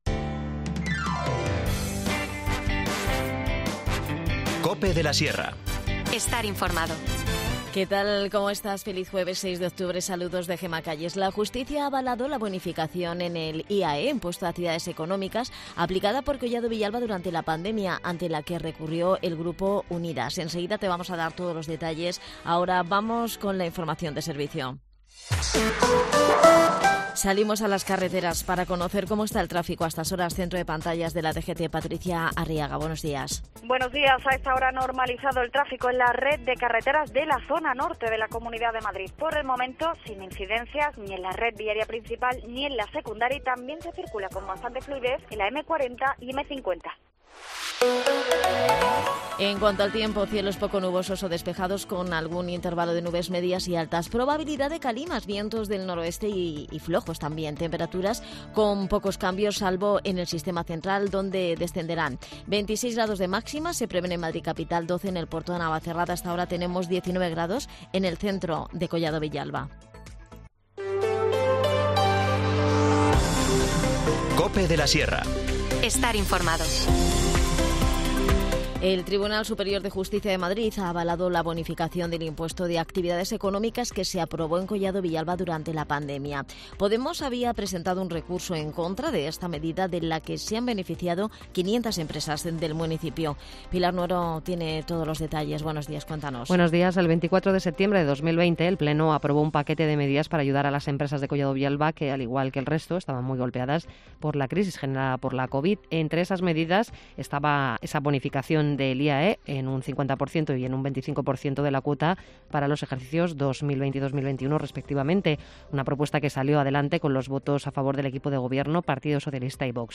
Un día en el que la Iglesia reza por los misioneros y colabora con las misiones. Charlamos de ello con nuestro párroco.